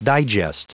Prononciation du mot digest en anglais (fichier audio)
Prononciation du mot : digest